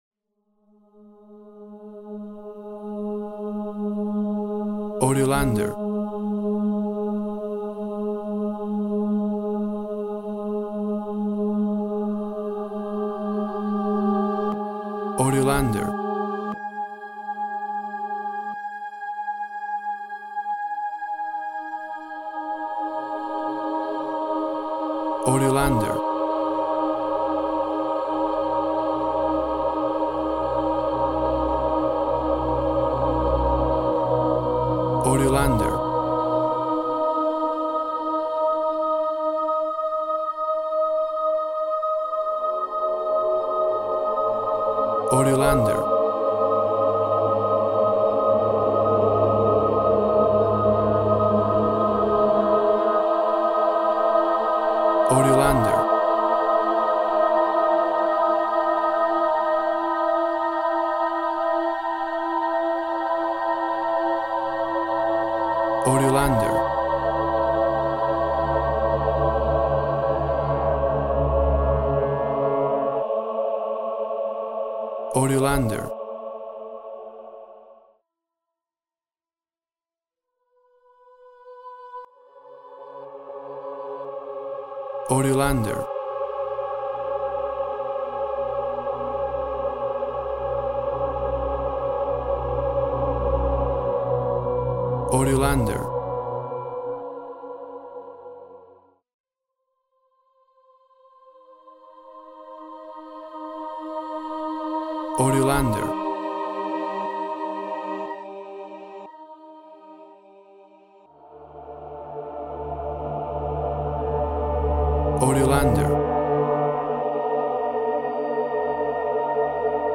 WAV Sample Rate 16-Bit Stereo, 44.1 kHz